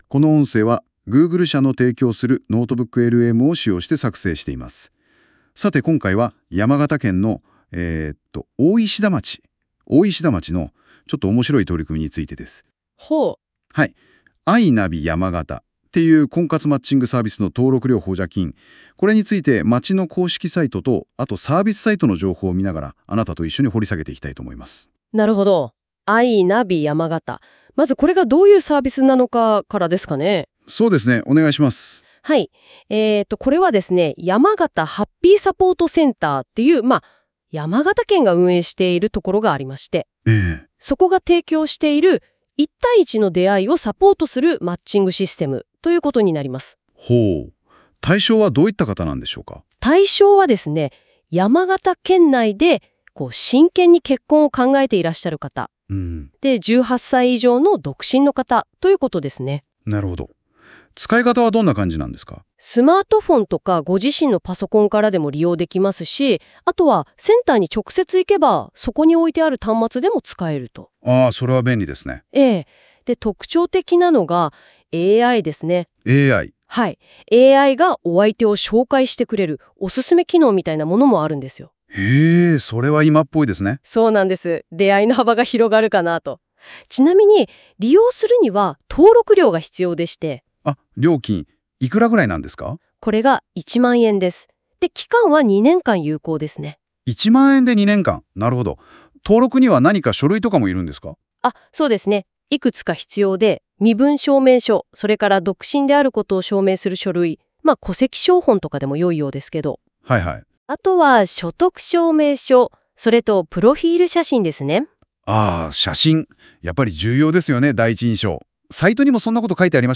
これは、Google社が提供するAIサービスで、補助金の要綱などの情報から音声要約を自動作成してラジオ風に紹介するものです。
AIによる自動読み上げの特性上、現時点では漢字の読み間違いや不明瞭な発音などがありますので、多少の誤読等はご容赦くださいますようお願いします。